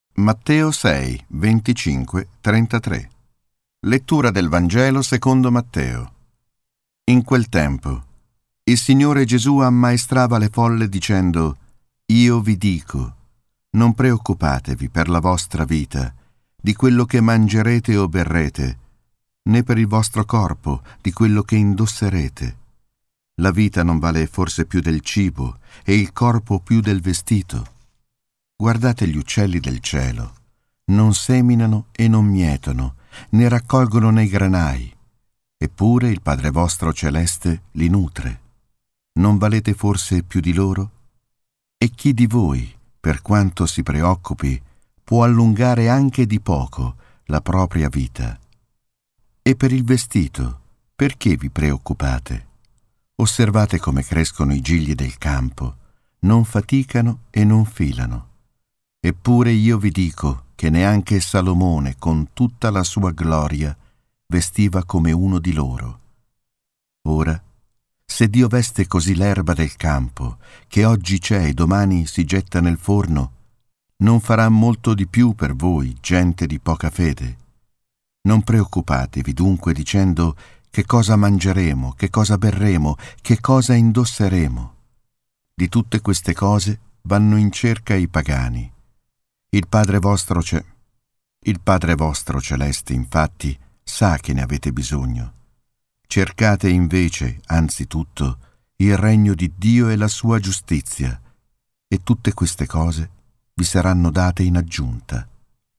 ✠ Lettura del Vangelo secondo Matteo